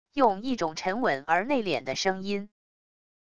用一种沉稳而内敛的声音wav音频